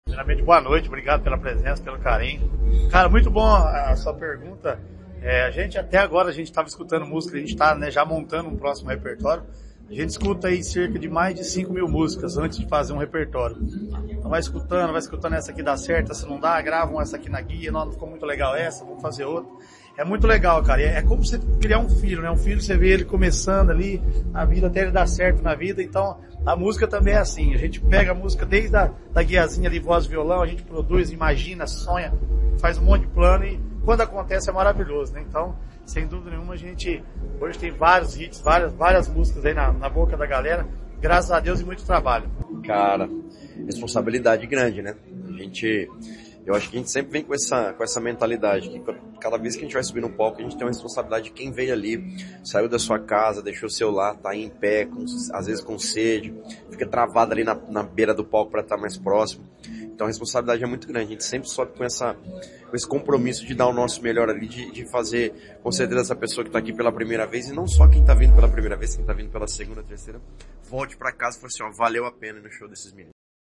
Sonora da dupla Zé Neto e Cristiano sobre a apresentação no Verão Maior Paraná